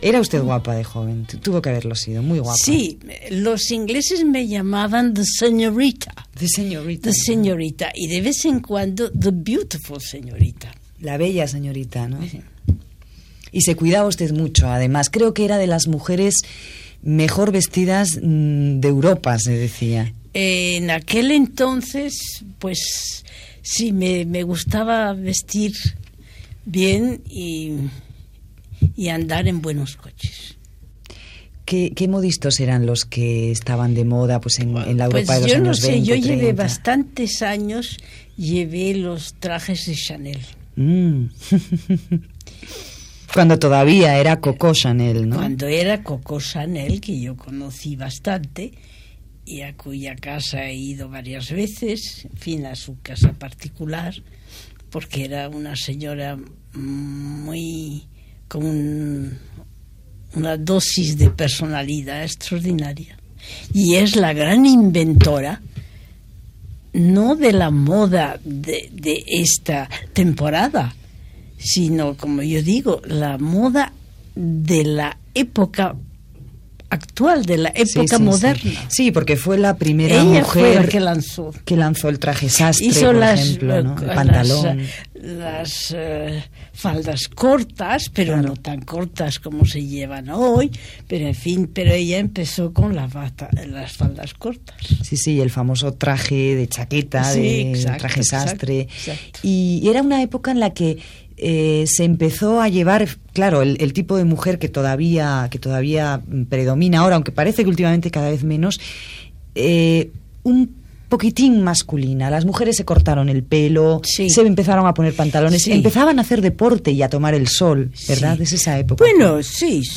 Fragment d'una entrevista a Lilí Álvarez en la qual parla d'ella i de la moda creada per Coco Chanel